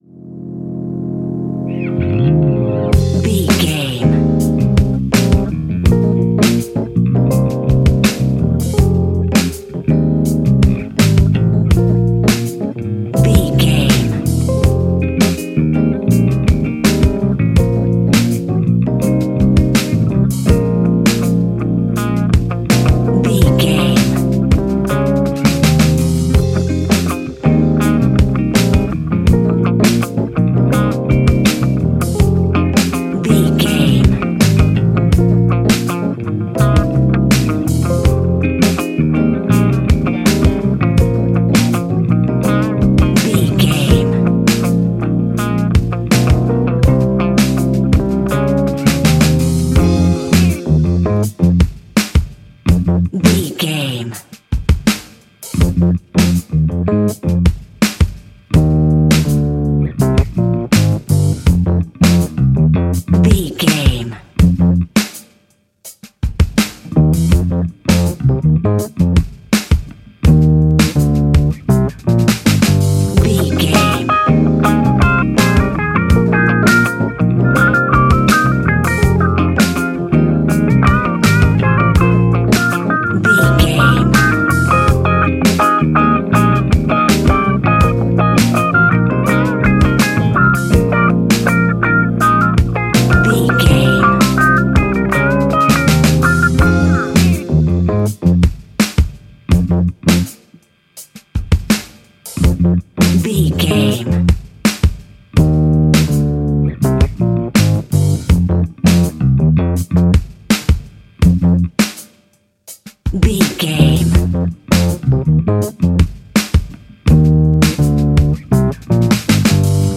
Epic / Action
Fast paced
In-crescendo
Uplifting
Ionian/Major
F♯
hip hop
instrumentals